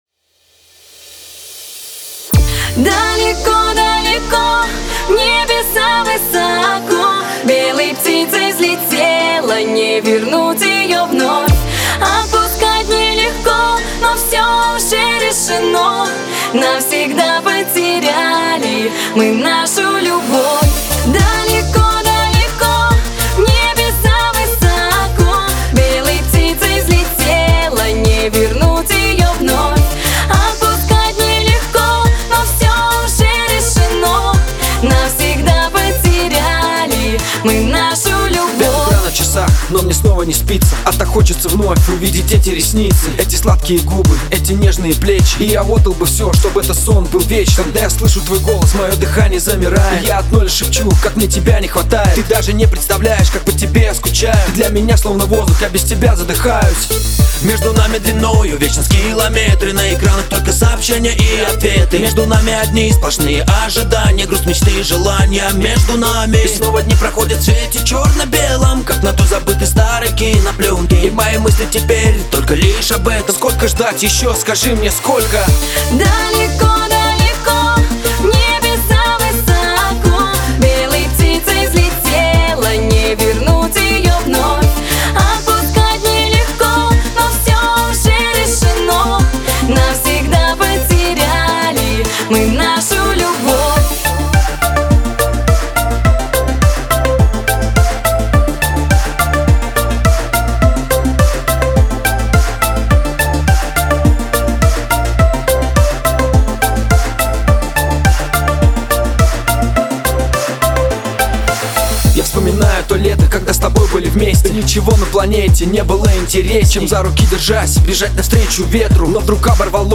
это запоминающаяся композиция в жанре хип-хоп